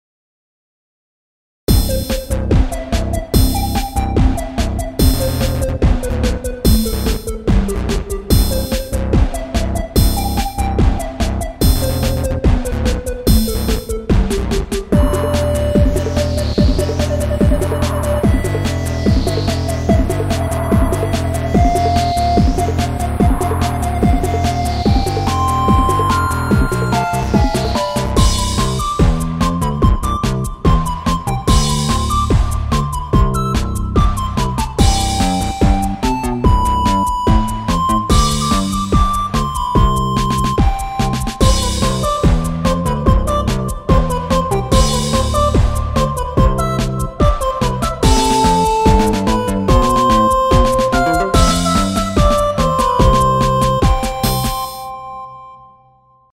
アップテンポショートニューエイジ明るい